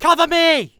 Voice Lines / Combat Dialogue